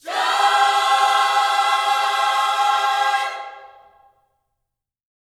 JOY CMAJ 4.wav